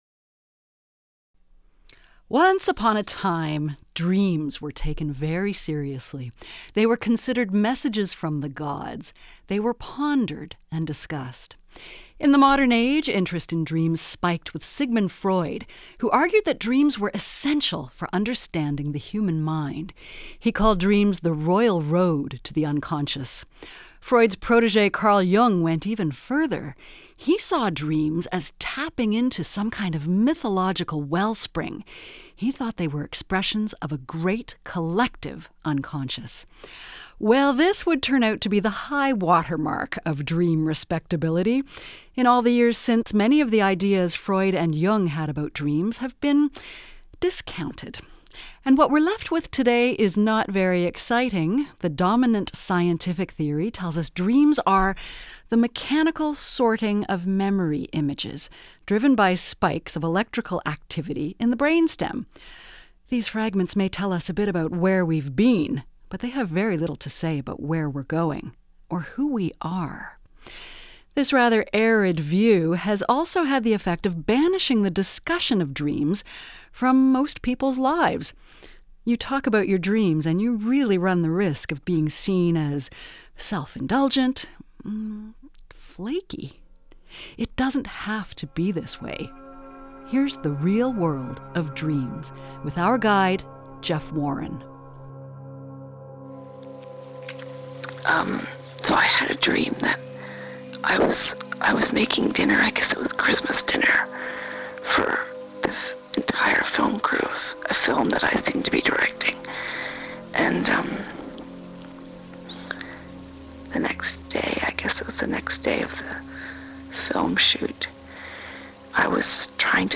Dreamy radio.
To add texture, three writer friends provide in-the-moment descriptions of their own strange nocturnal effusions.